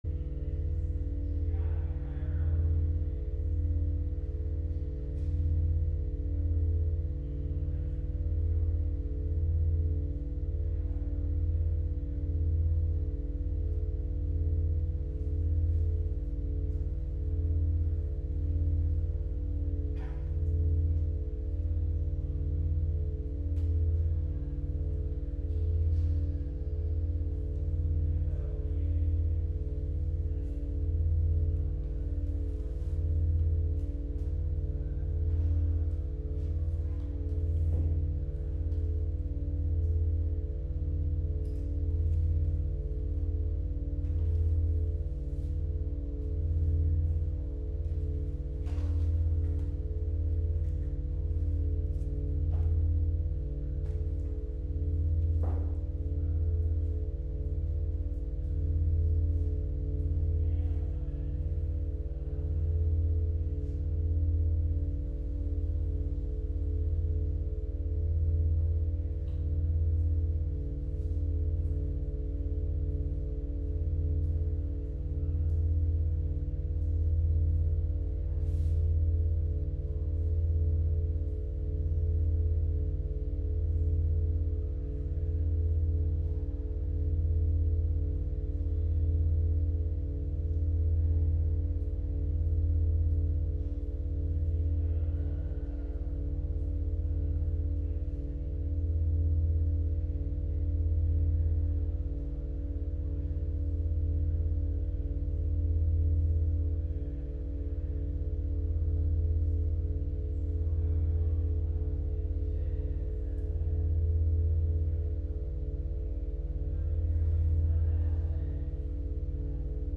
live from the CT::SWaM Plasticity Office at Fridman Gallery